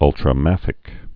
(ŭltrə-măfĭk)